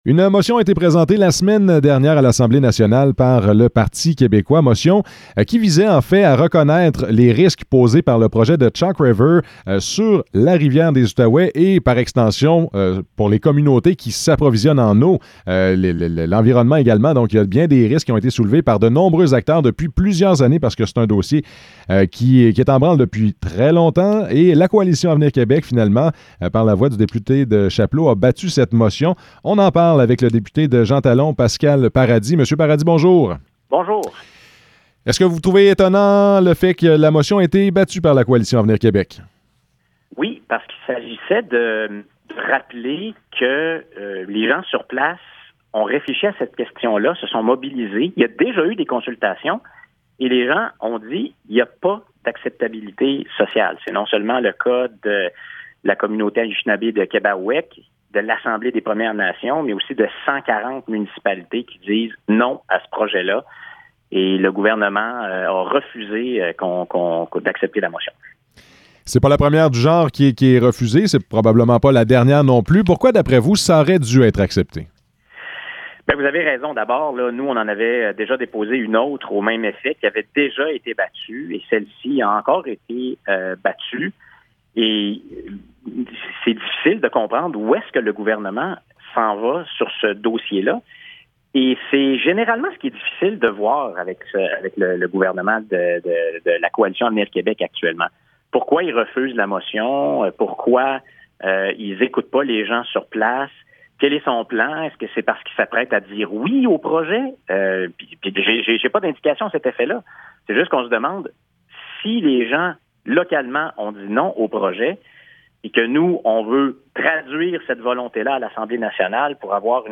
Entrevue avec Pascal Paradis
entrevue-avec-pascal-paradis.mp3